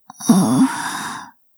语气词-害羞.wav